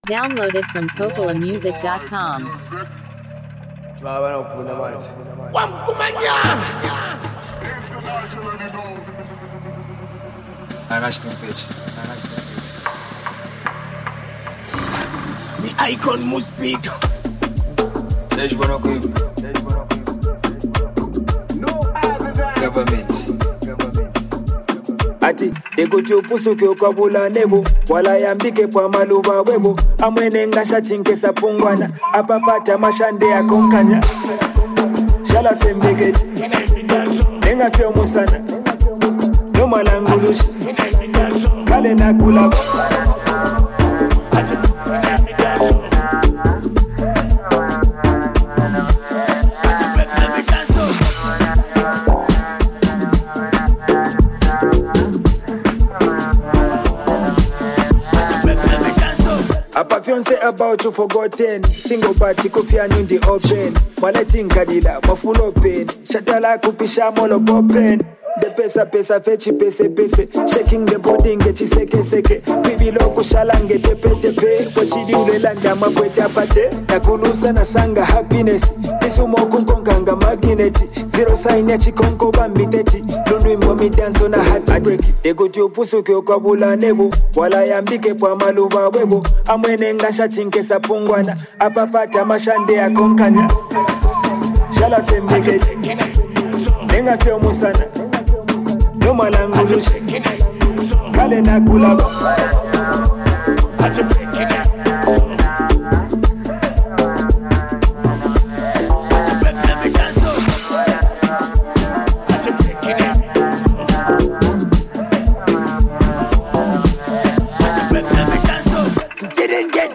Zambian hip-hop collaboration
calm but heavy lyrical presence